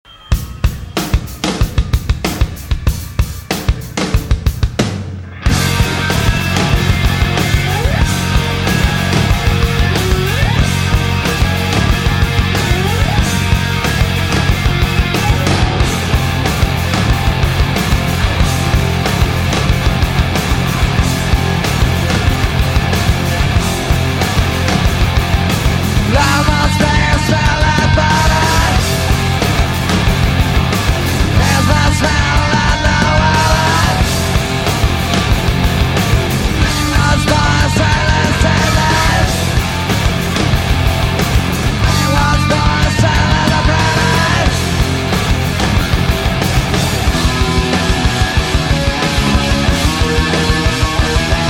- Mixage de qualité